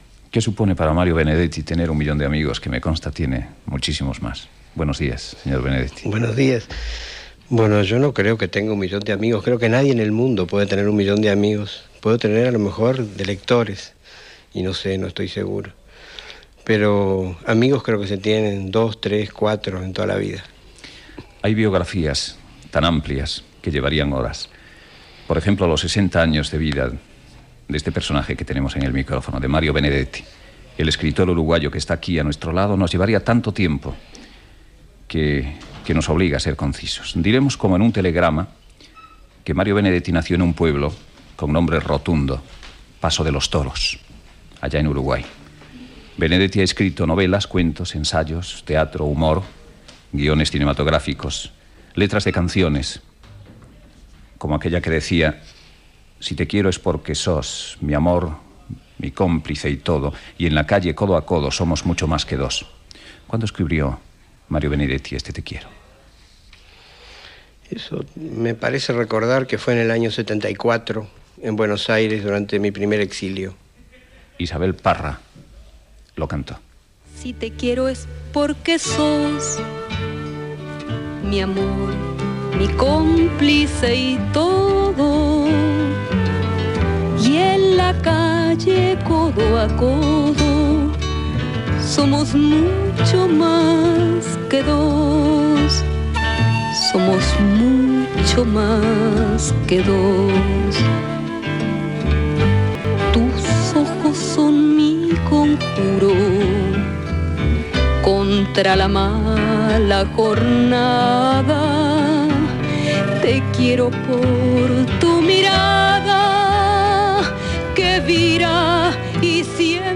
Entrevista a l'escriptor Mario Benedetti. Perfil biogràfic, poema "Te quiero" interpretat per Isabel Parra, la seva feina a la ràdio francesa, la seva vida a Cuba, etc.
Info-entreteniment